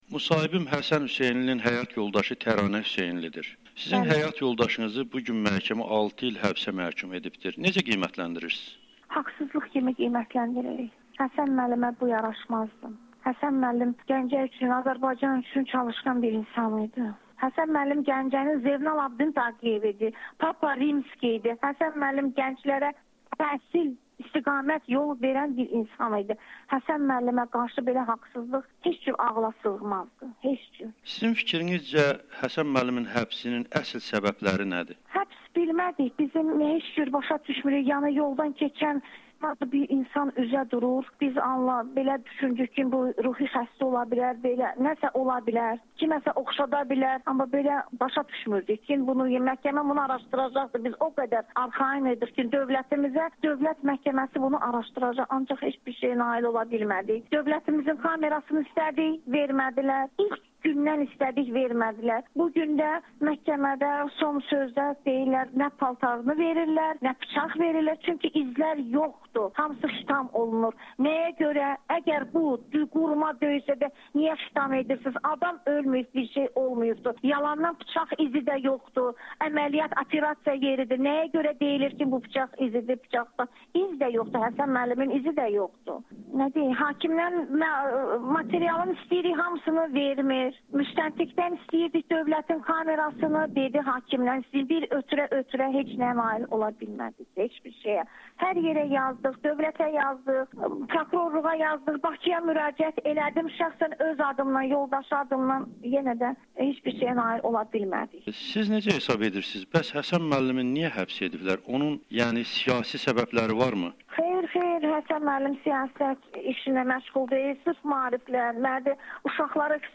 [Audio-Müsahibə]
by Amerikanın Səsi